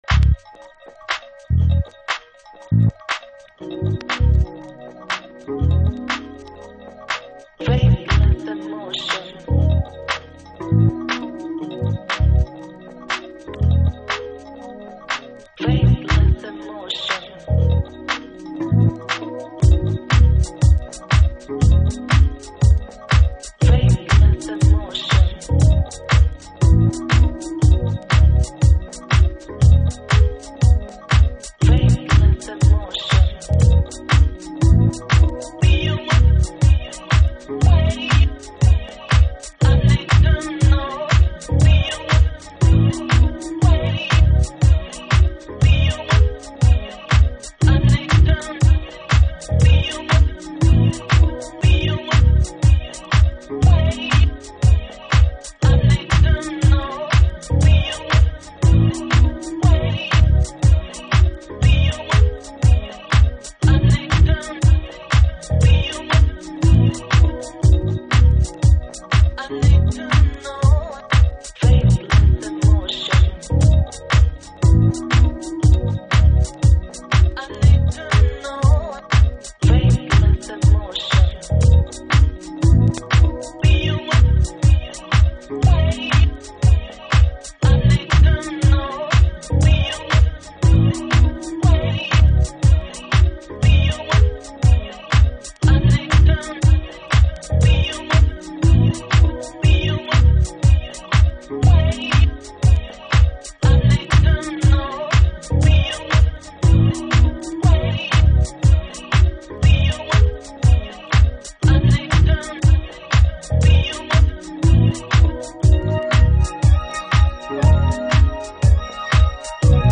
House / Techno
JAZZからの影響が強いと自身でも語っていたドラミング、なめらかな鍵盤。